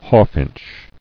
[haw·finch]